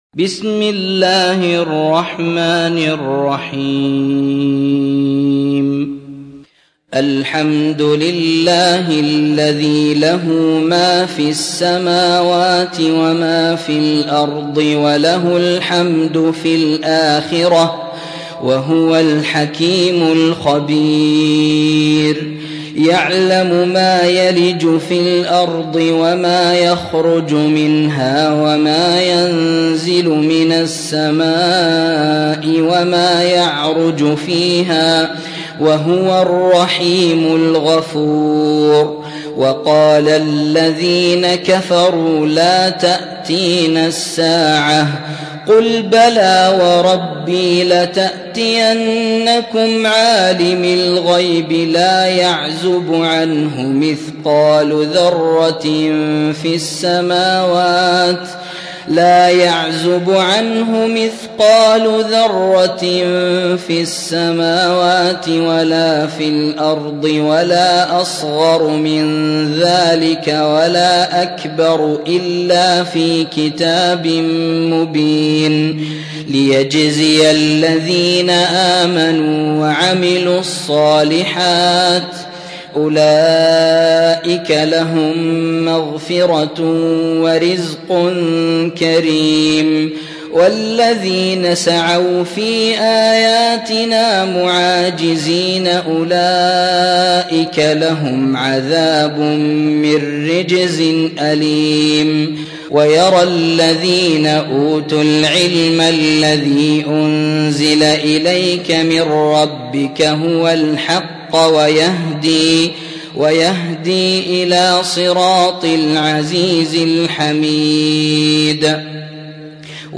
34. سورة سبأ / القارئ